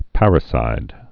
(părĭ-sīd)